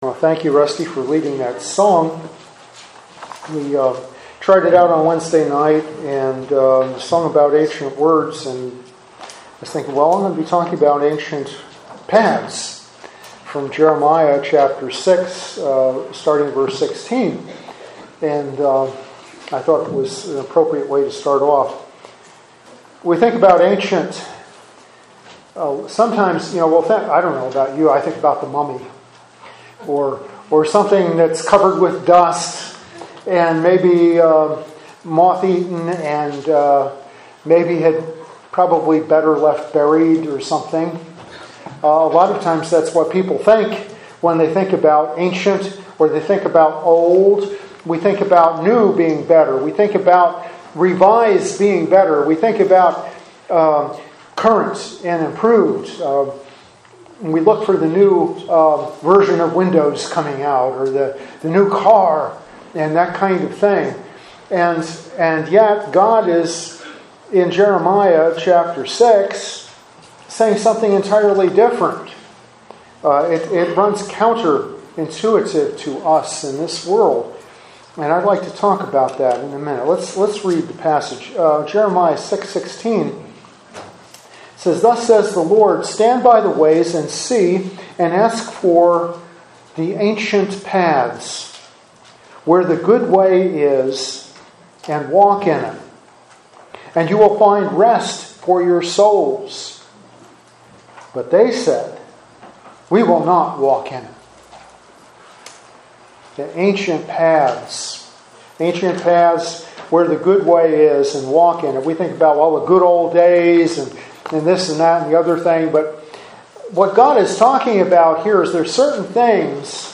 Sermons on Jeremiah